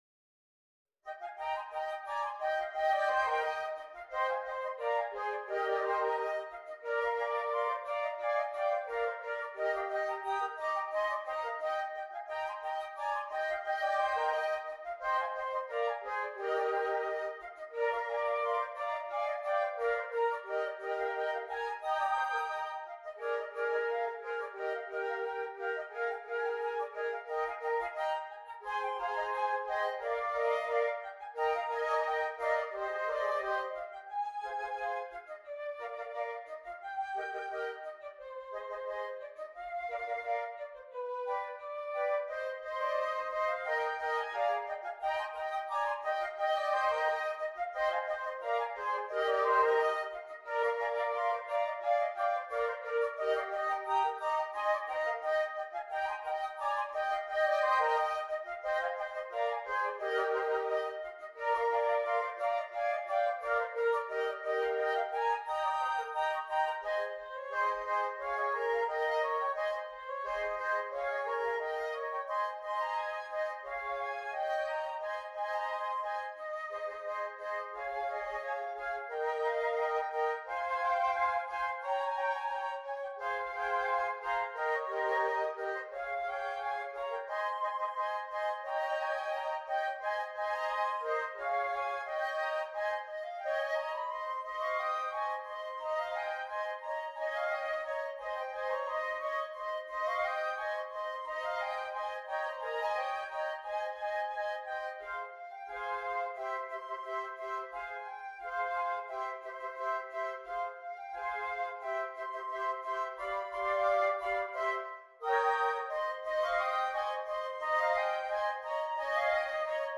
4 Flutes
There are fun interacting parts for all players.